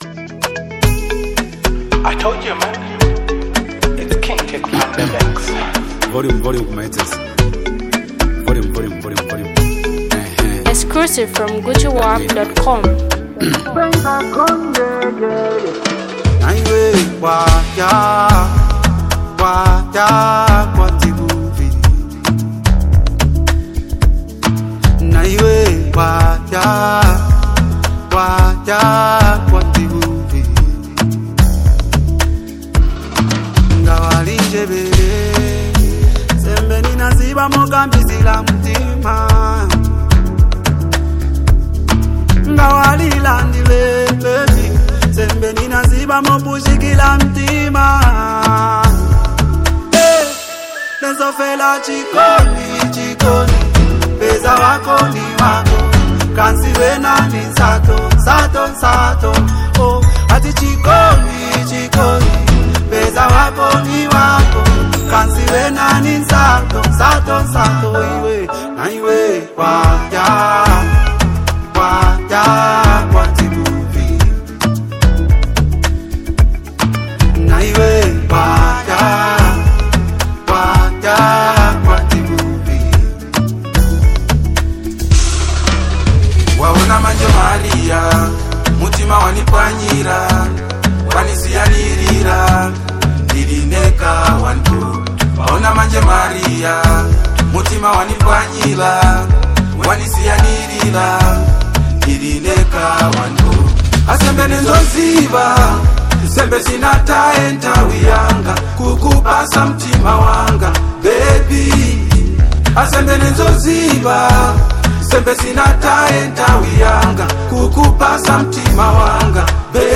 Vibrant soundscapes
Zambian music